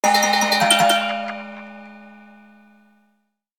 alert complete ding effect fantasy magic notification sfx sound effect free sound royalty free Sound Effects